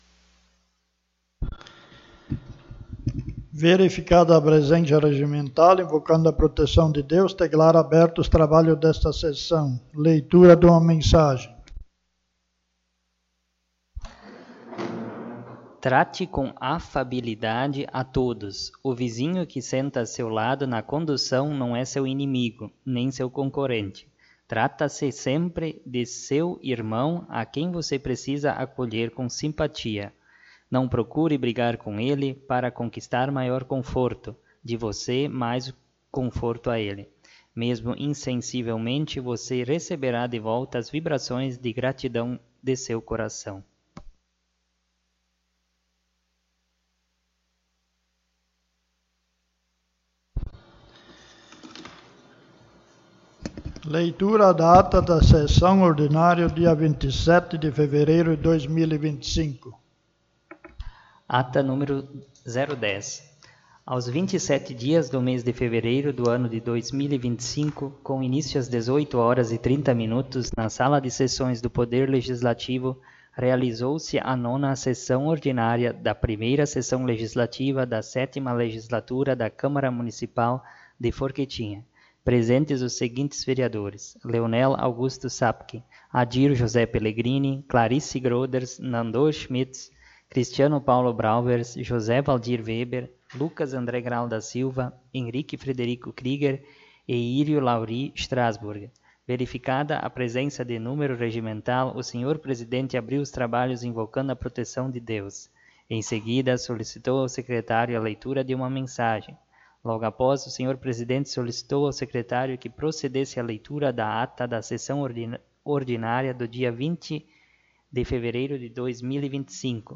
10ª Sessão Ordinária
Câmara de Vereadores de Forquetinha